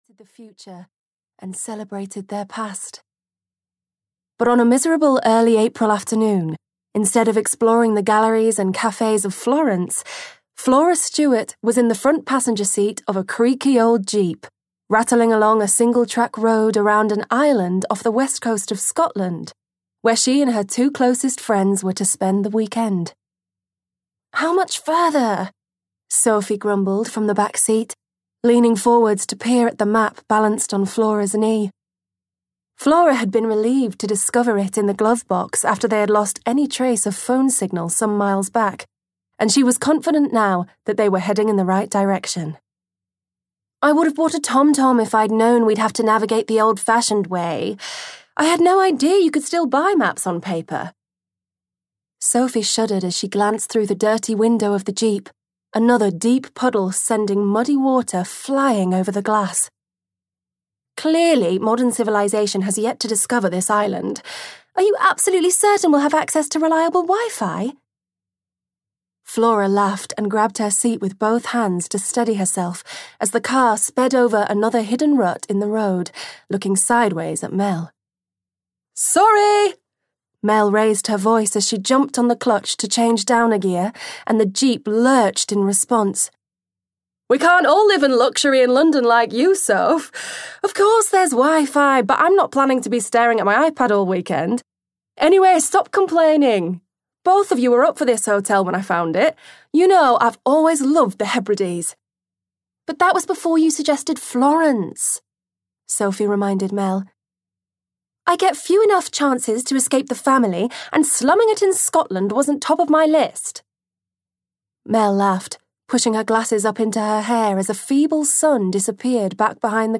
The Garden of Little Rose (EN) audiokniha
Ukázka z knihy